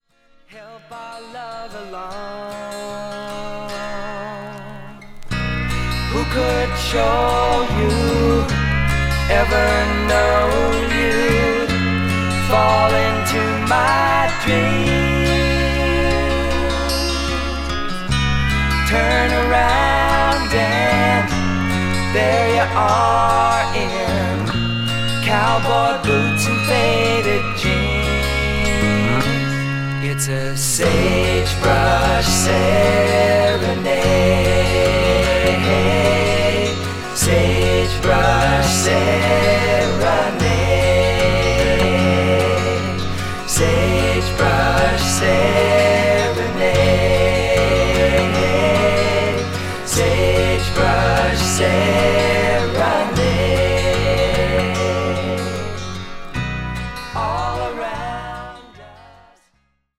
トラディショナルなカントリー・ロック・サウンドを中心にしたアルバムです。